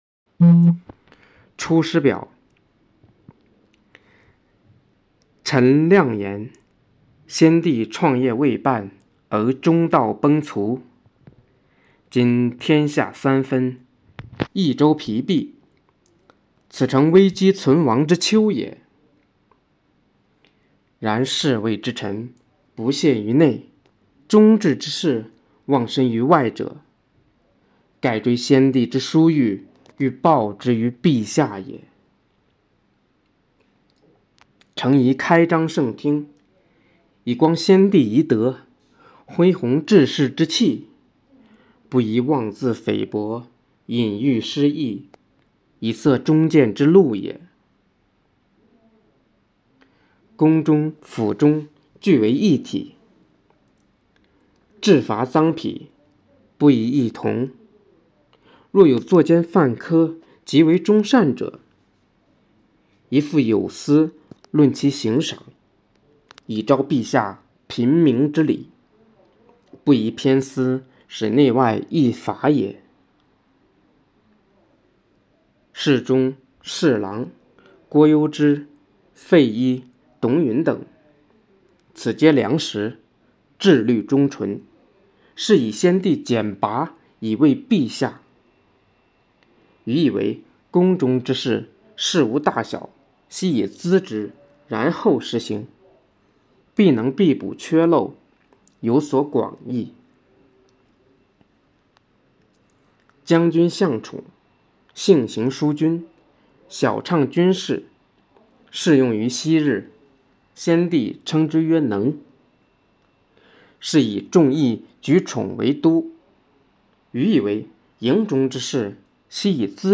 比赛过程中，广大师生积极参与，创作了许多优秀作品，以下为“诵读中国”经典诵读大赛教师组和学生组中的优秀作品展示。